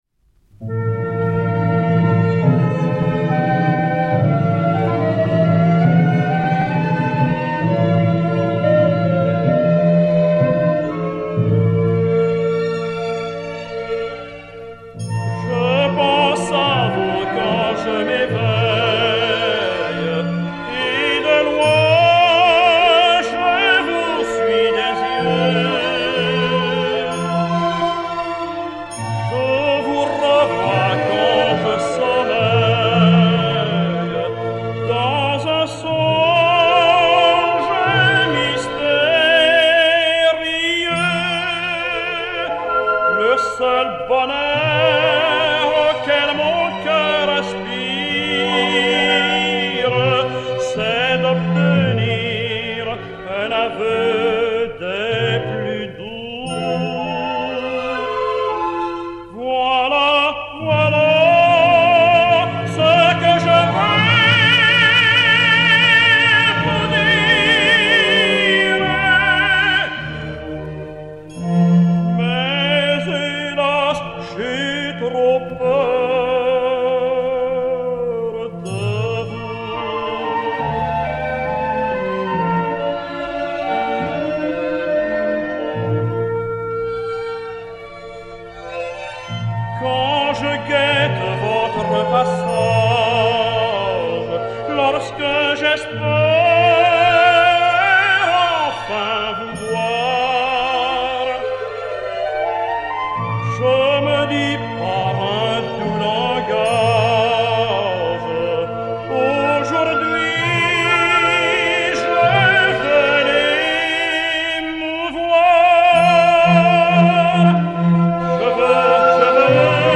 Romance